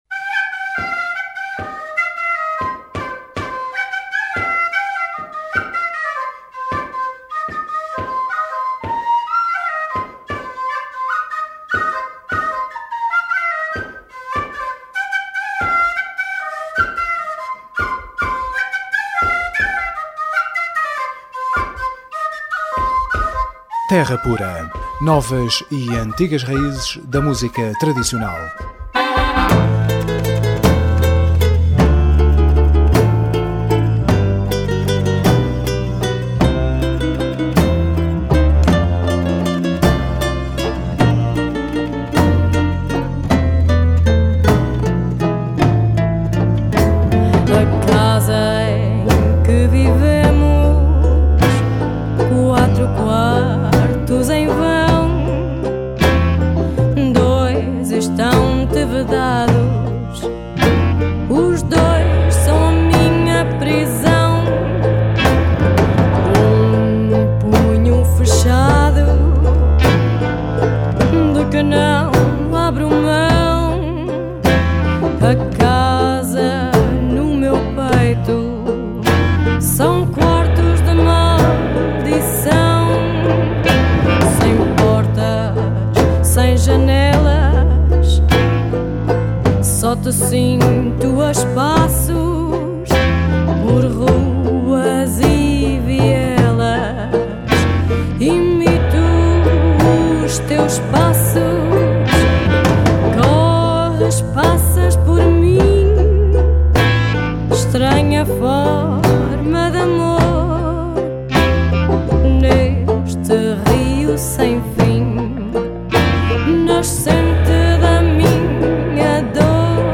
Entrevista Soaked Lamb by Terra Pura Radio on Mixcloud